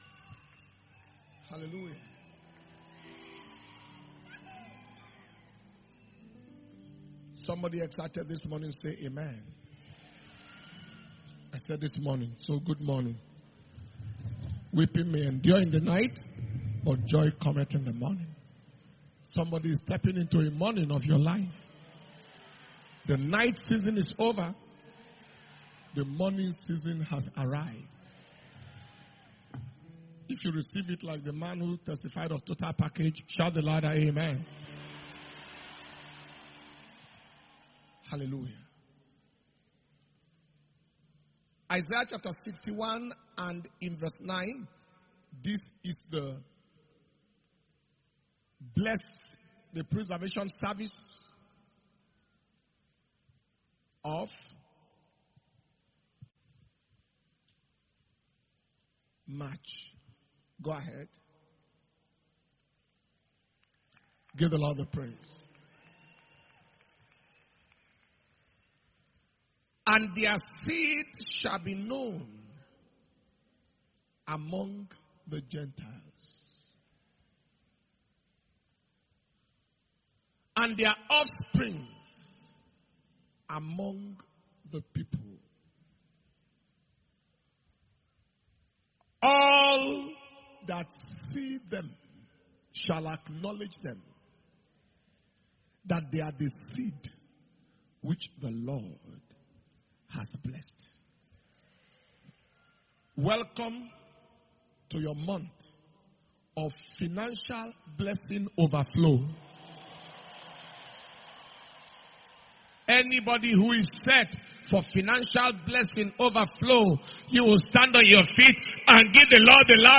March 2022 Preservation And Power Communion Service – Wednesday 2nd March 2022